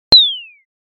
SE（ビーム）
ビーム。ダッシュ。ピュー。